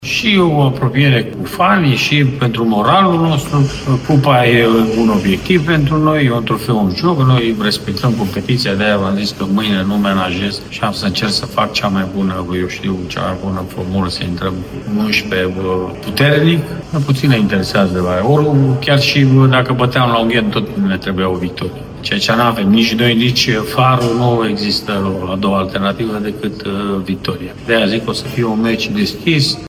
Rednic a vorbit și despre importanţa unui succes astăzi: